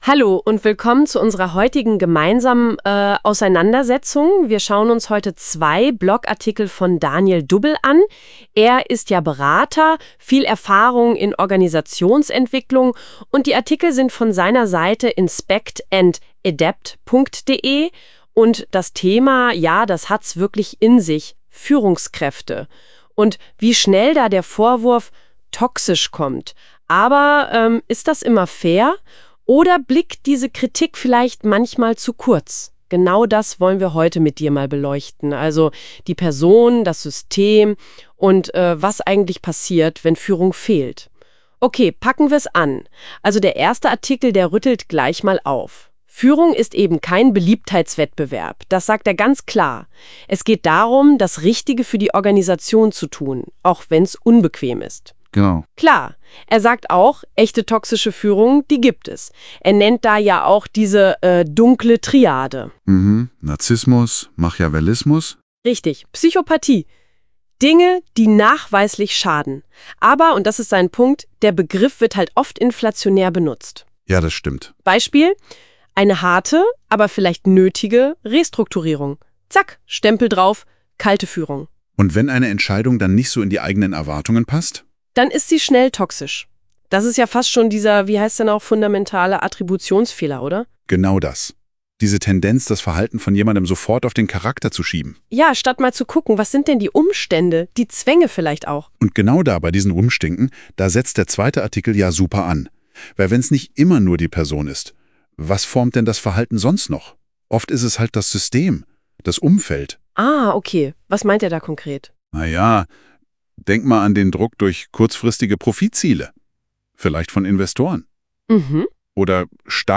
Hier bekommst du einen durch NotebookLM generierten KI-Podcast Dialog zu meinen Artikeln rund um toxische Führung.